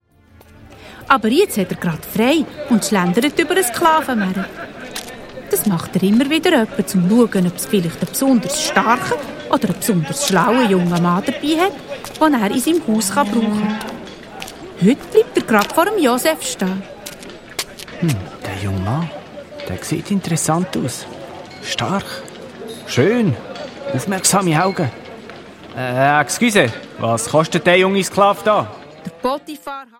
Zusätzlich enthalten: Mehrere Songs, das Hörspiel «Iifersucht» der lustigen Bärenkinder der Adonia-KidsParty
Hörspiel-Album